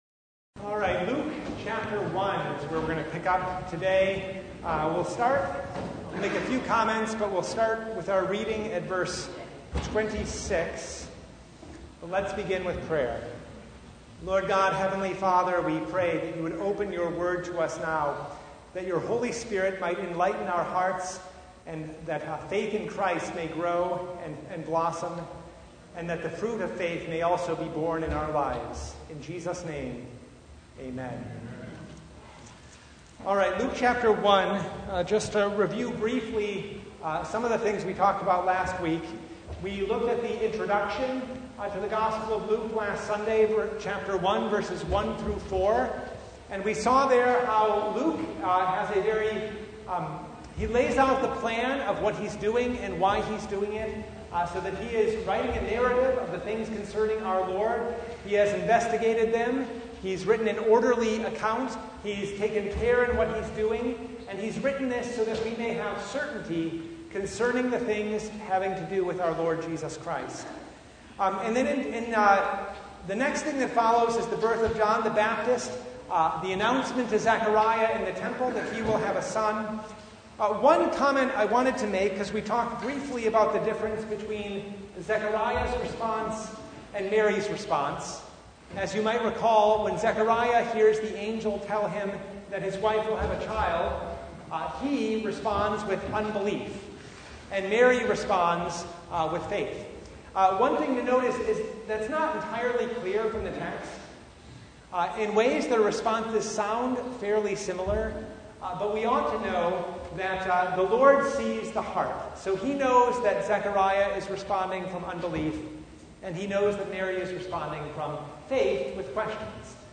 Passage: Luke 1 26:56 Service Type: Bible Study